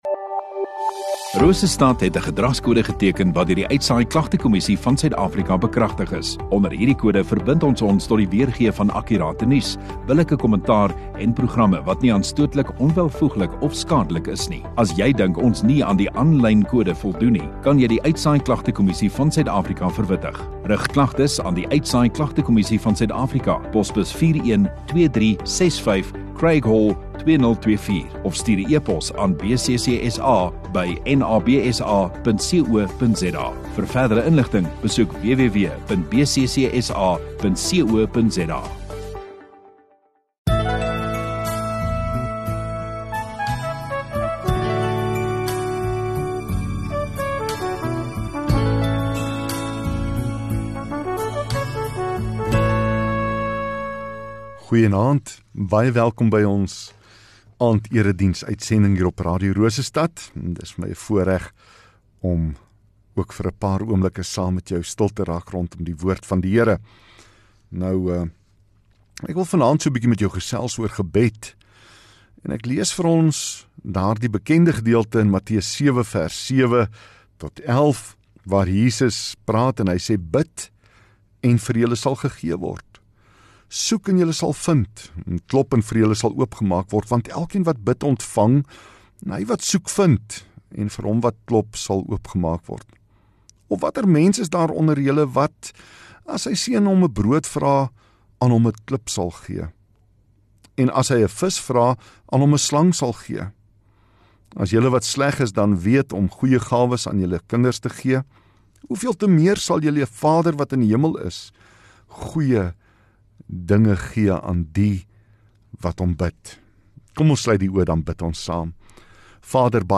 28 Apr Sondagaand Erediens